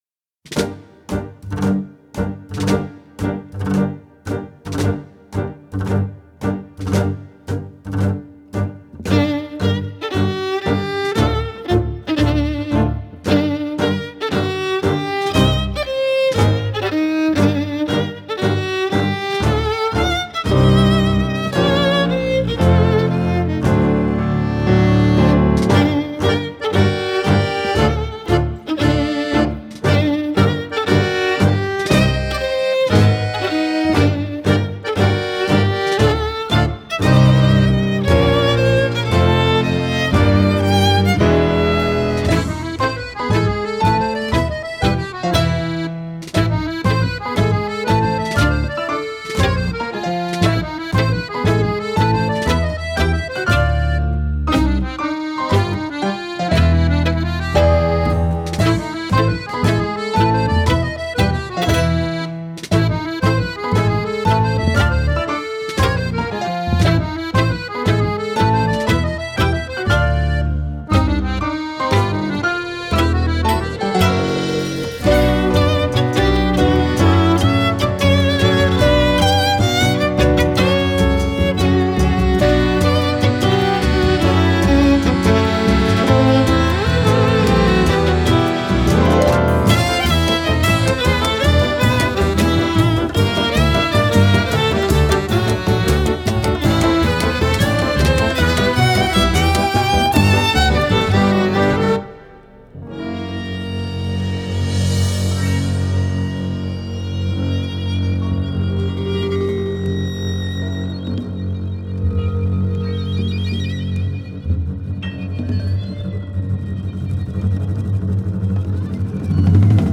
Genre: Jazz, World, Accordion